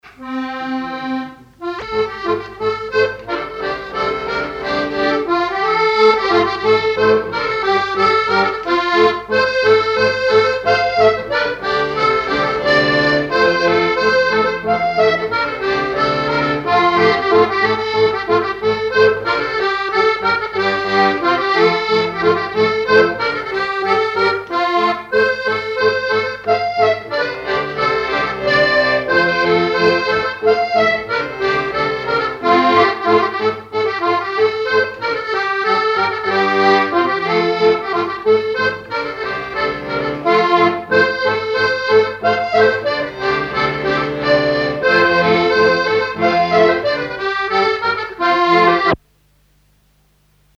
Mémoires et Patrimoines vivants - RaddO est une base de données d'archives iconographiques et sonores.
danse : mazurka
Répertoire sur accordéon diatonique
Pièce musicale inédite